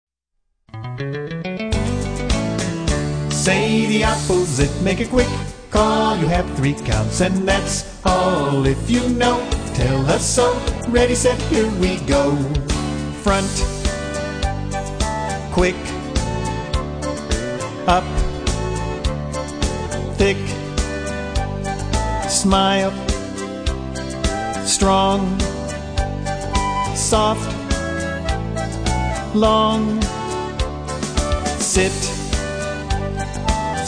A movement song for teaching opposites.